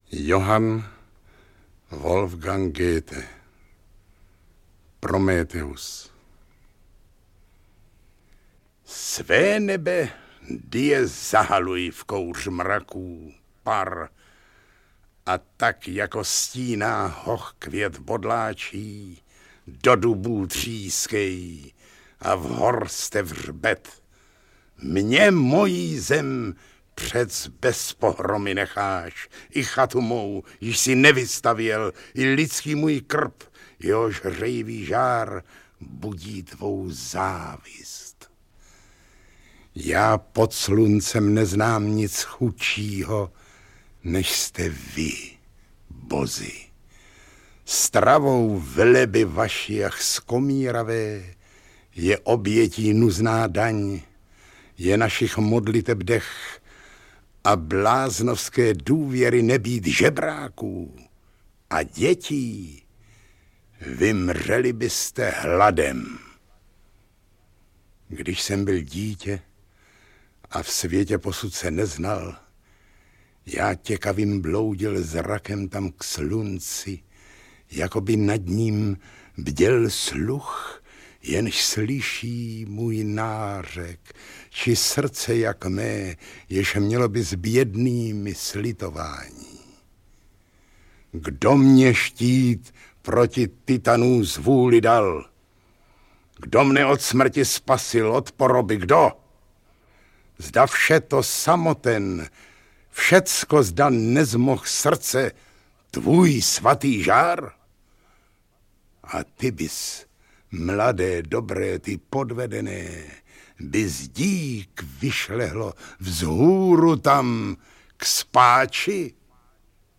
• Čte: Zdeněk Štěpánek